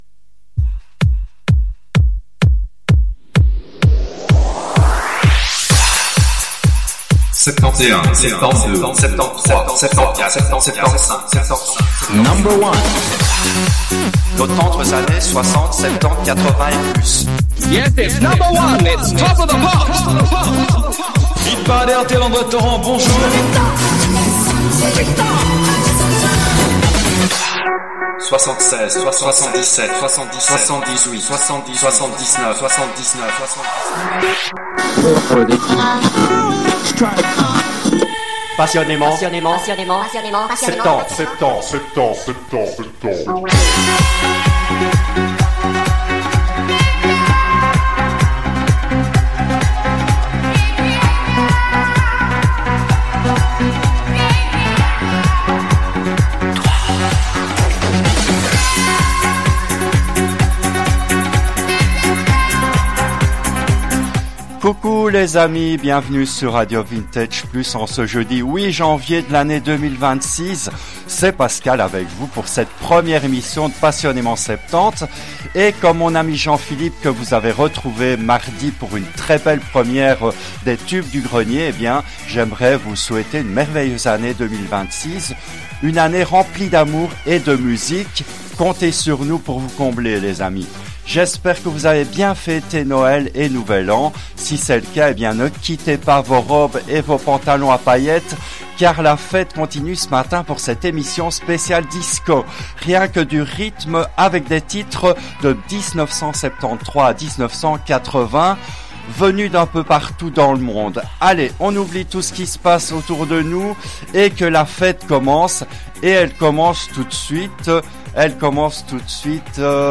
des titres DISCO de 1973 à 1980